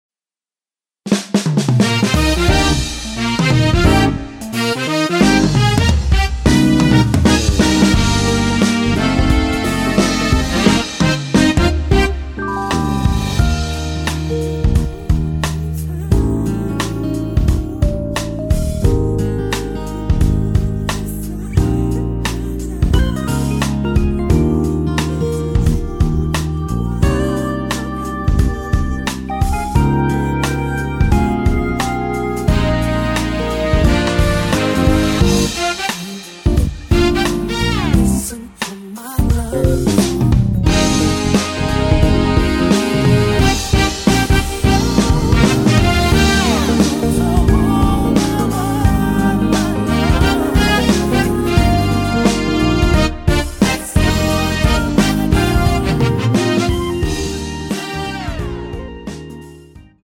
코러스 포함된 MR 입니다.(미리듣기 참조)
Eb
앞부분30초, 뒷부분30초씩 편집해서 올려 드리고 있습니다.